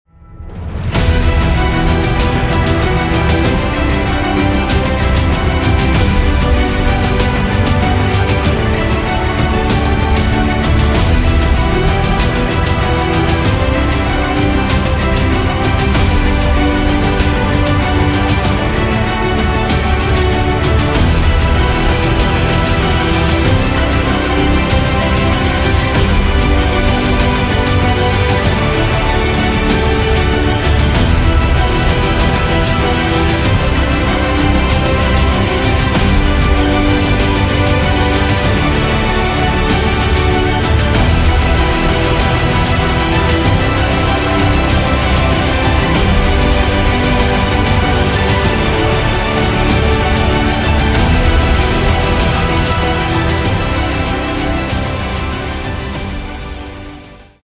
Works with voiceovers or visuals alone.